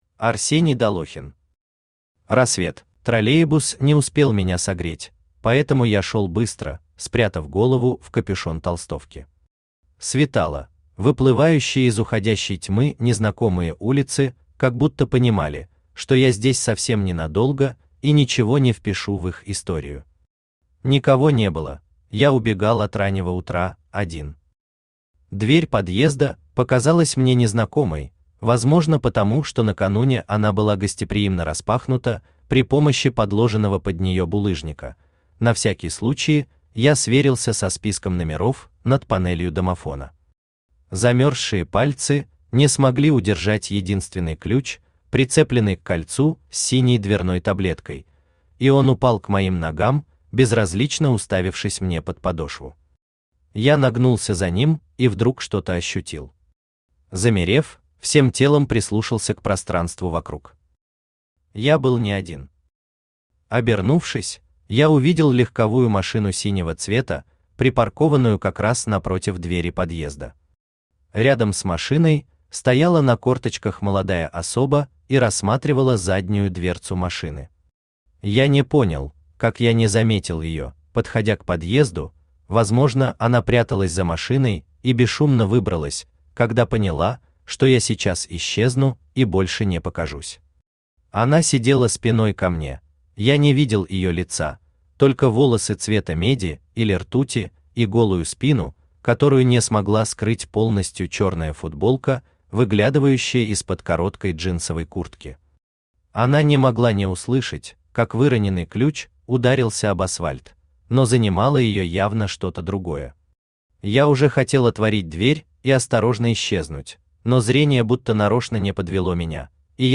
Аудиокнига Рассвет | Библиотека аудиокниг
Aудиокнига Рассвет Автор Арсений Долохин Читает аудиокнигу Авточтец ЛитРес.